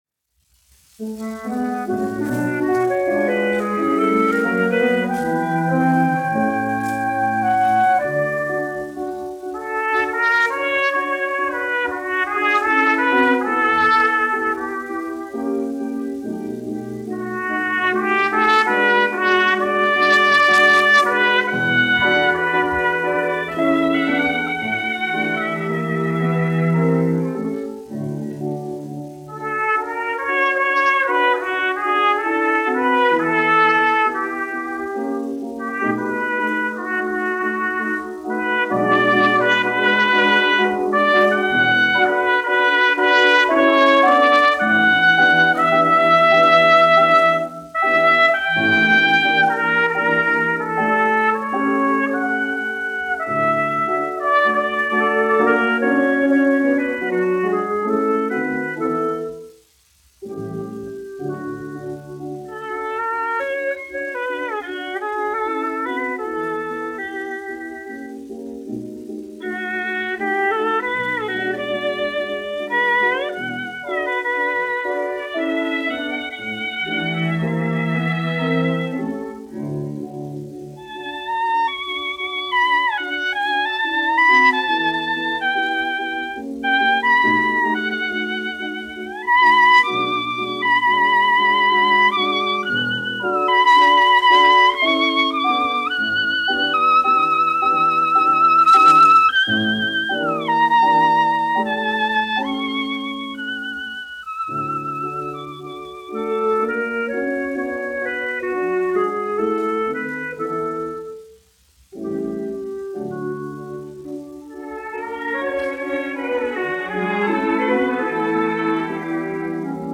1 skpl. : analogs, 78 apgr/min, mono ; 25 cm
Orķestra mūzika, aranžējumi
Skaņuplate
Latvijas vēsturiskie šellaka skaņuplašu ieraksti (Kolekcija)